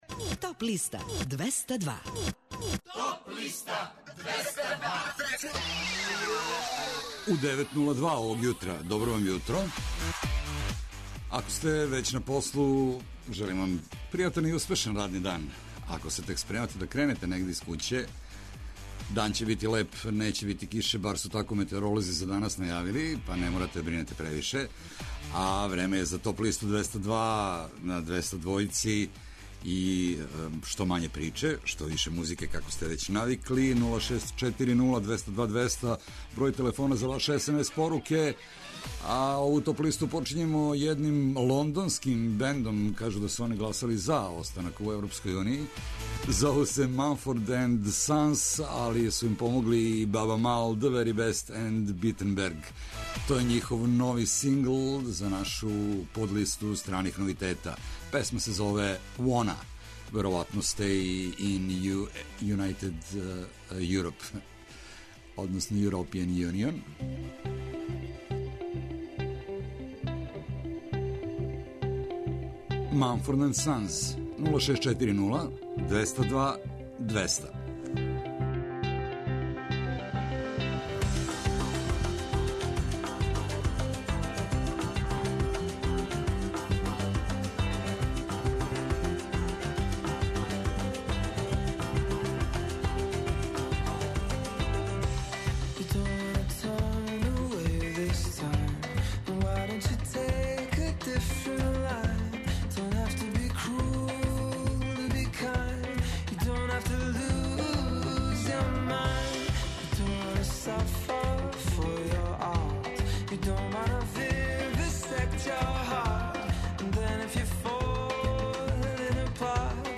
Најавићемо актуелне концерте, подсетићемо се шта се битно десило у историји музике у периоду од 27. јуна до 1. јула. Емитоваћемо песме са подлиста лектире, обрада, домаћег и страног рока, филмске и инструменталне музике, попа, етно музике, блуза и џеза, као и класичне музике.